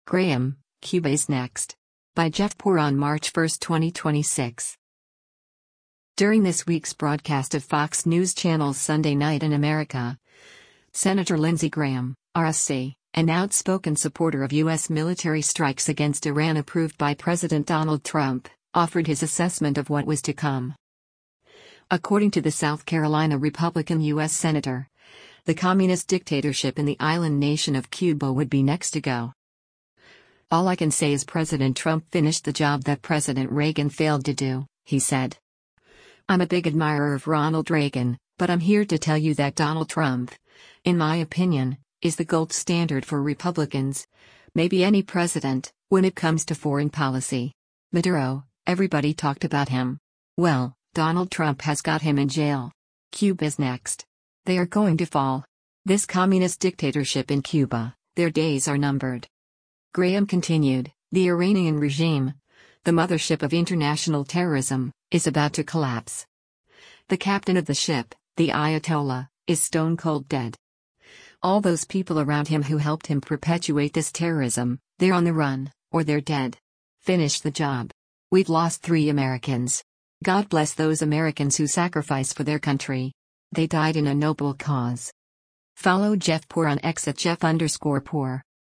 During this week’s broadcast of Fox News Channel’s “Sunday Night in America,” Sen. Lindsey Graham (R-SC), an outspoken supporter of U.S. military strikes against Iran approved by President Donald Trump, offered his assessment of what was to come.